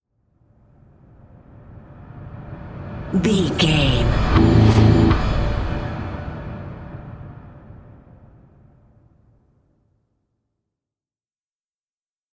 Thriller
Aeolian/Minor
synthesiser
drum machine